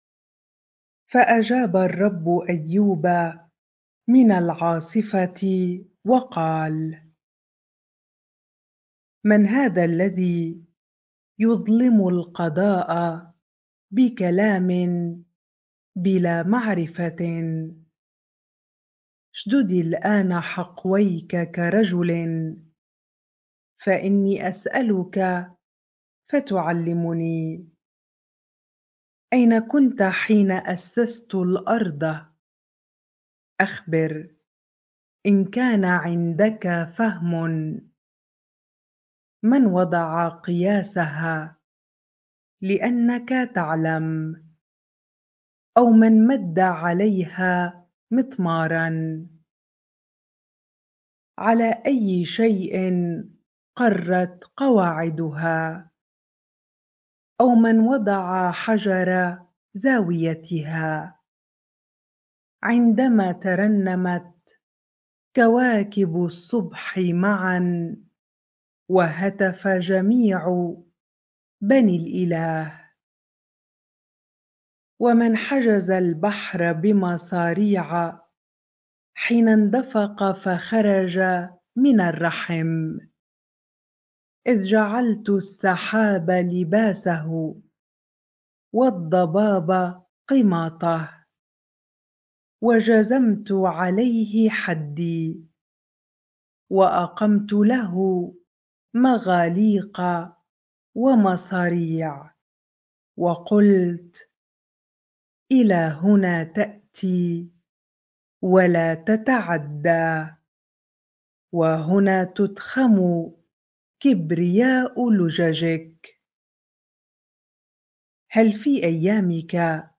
bible-reading-Job 38 ar